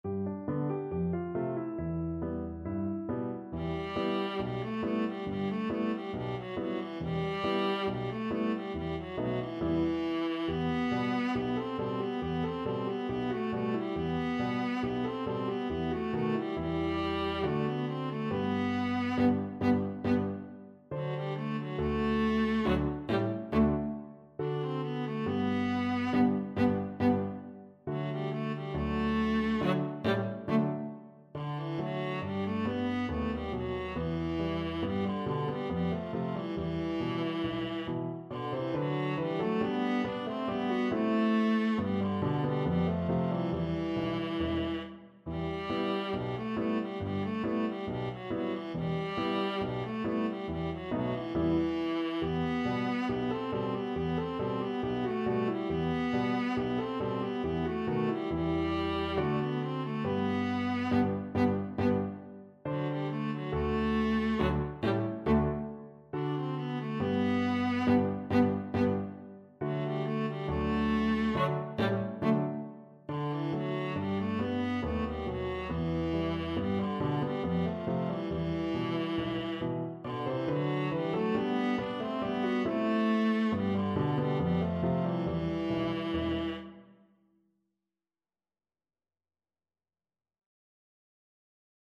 Viola
Traditional Music of unknown author.
E minor (Sounding Pitch) (View more E minor Music for Viola )
2/4 (View more 2/4 Music)
Slow =69
World (View more World Viola Music)
patch_tanz_VLA.mp3